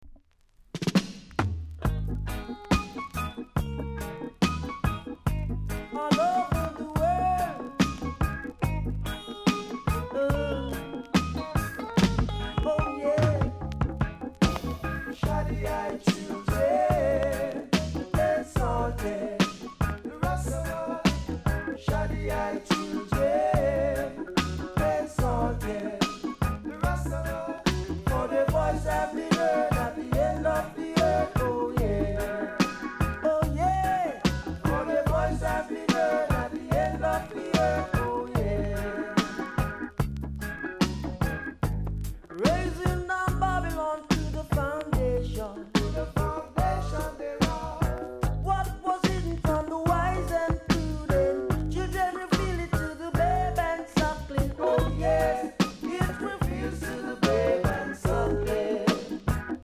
※小さなチリ、パチノイズが少しあります。
コメント DEEP 80's ROOTS VOCAL!!RARE ORIGINAL!!